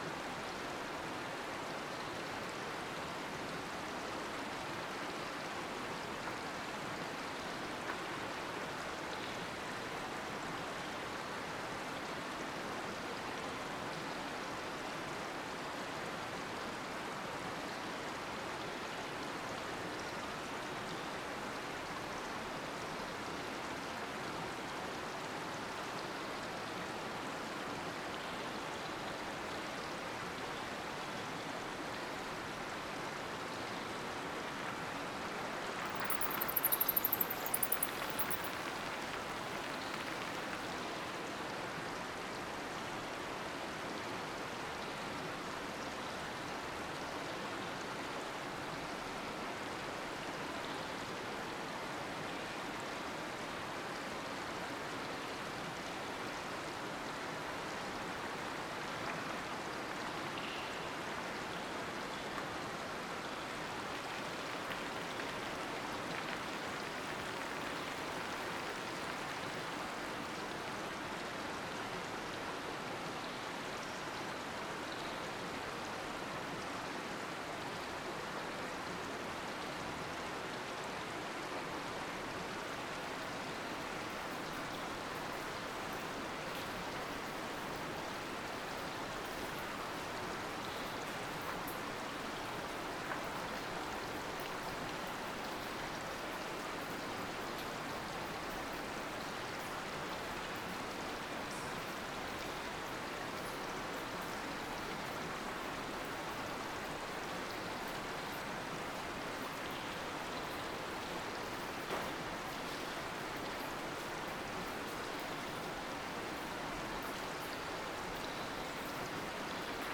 Underground River.ogg